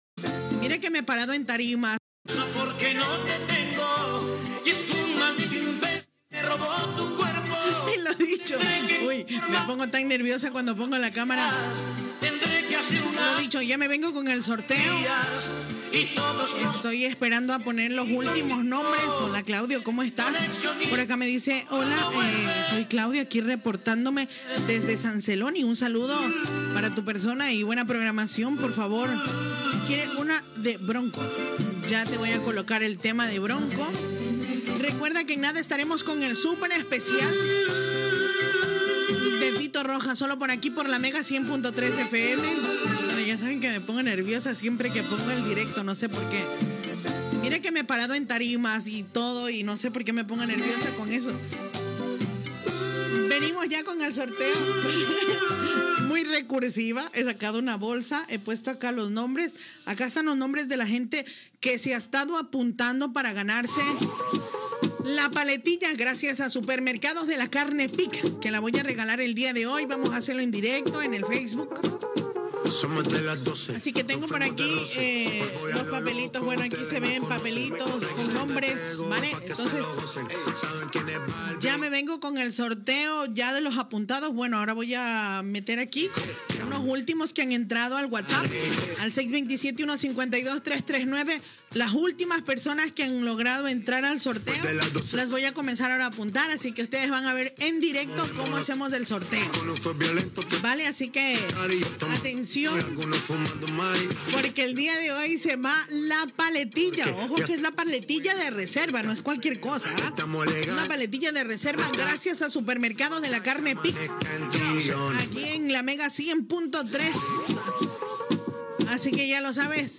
Petició d'un oïdor, identificació de l'emissora i sorteig d'un pernil